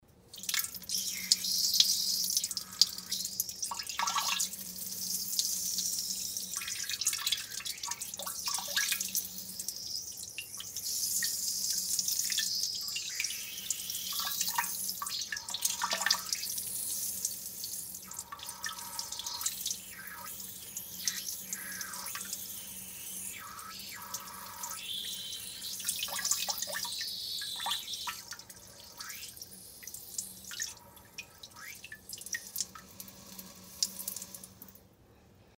Звуки струй в унитазе с журчанием